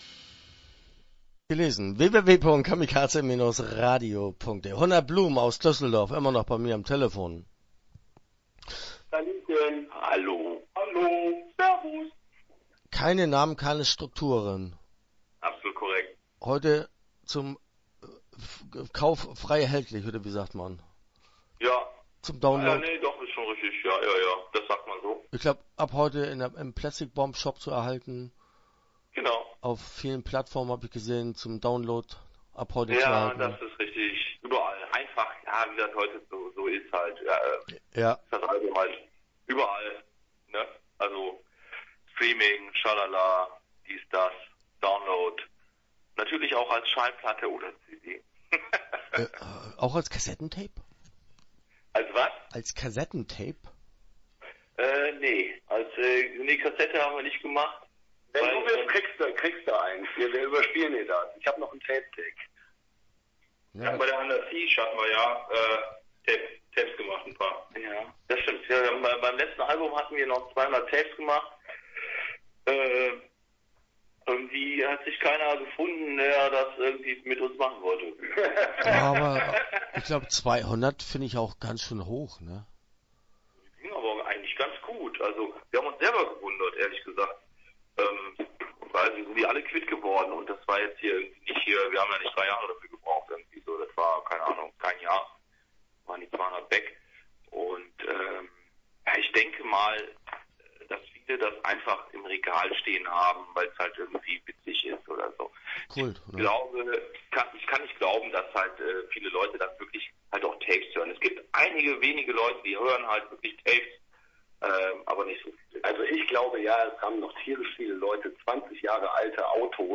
100blumen - Interview Teil 1 (11:19)